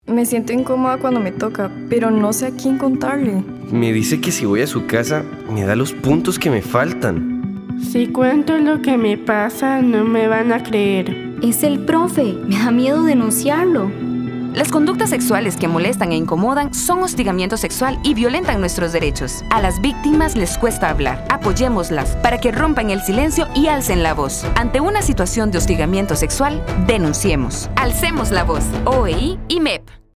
Audio de la campaña radial de comunicación Alcemos la voz contra el hostigamiento. Público meta estudiantes